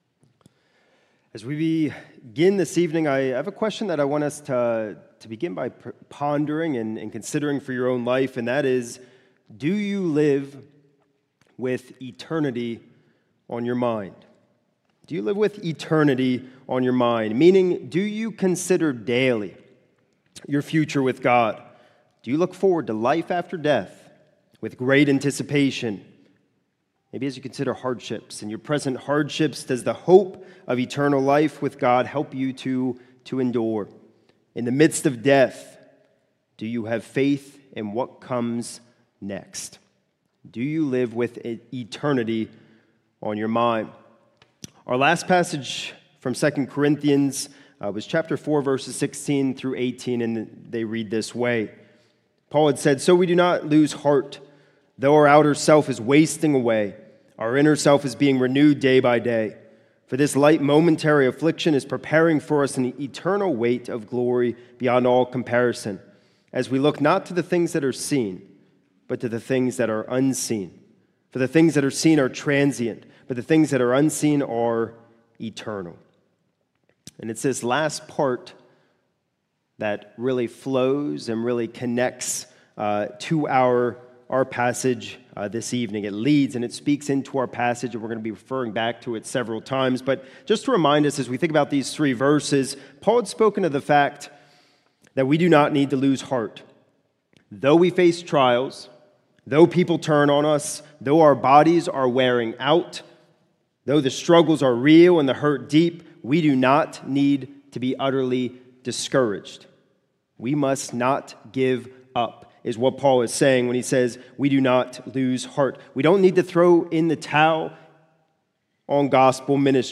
This is a sermon recorded at the Lebanon Bible Fellowship Church in Lebanon, PA during the evening worship service on 12/28/2025